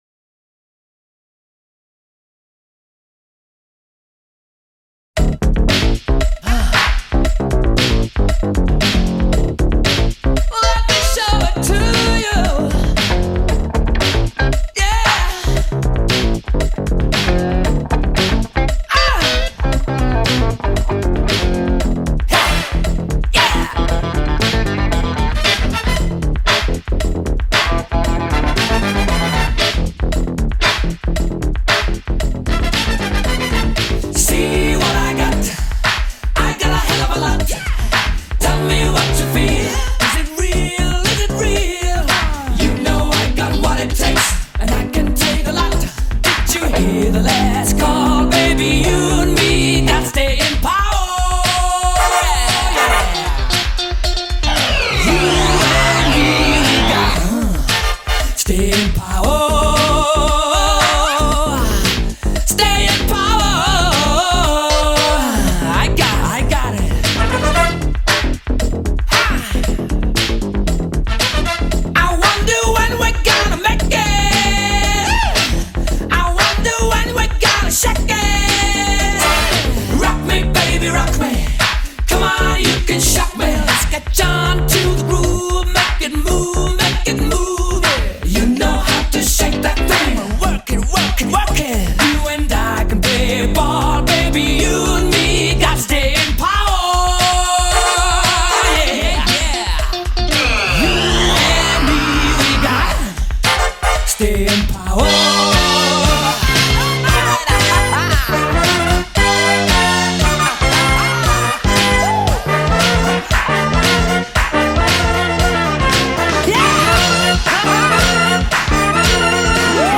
Rock, Pop Rock